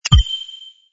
ui_execute_transaction.wav